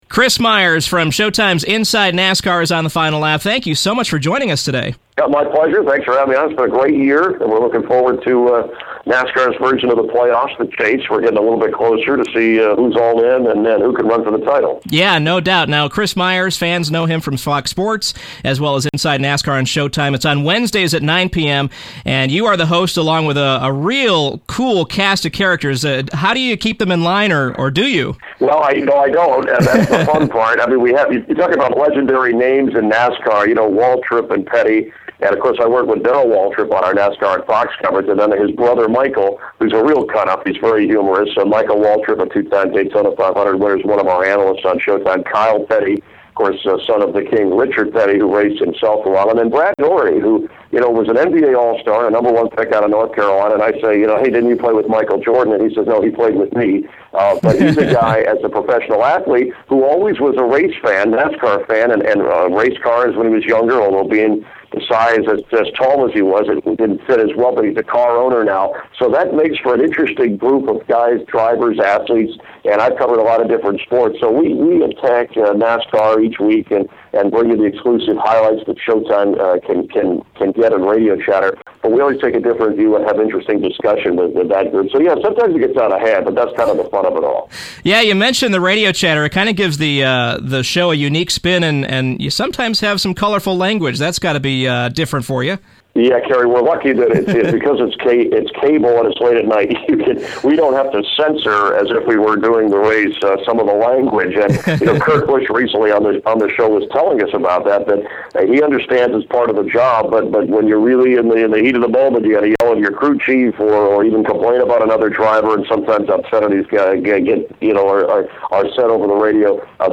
Interview: Chris Myers from Showtime’s Inside NASCAR